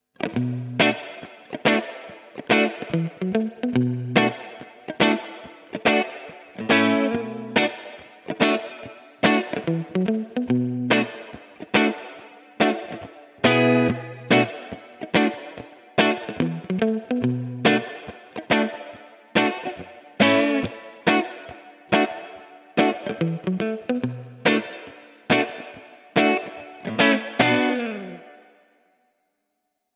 Spring 65 Modulation